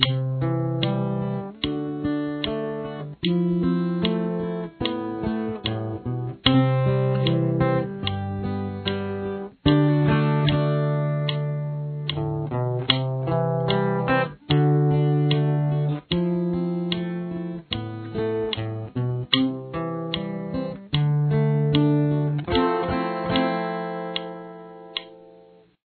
Verse Interlude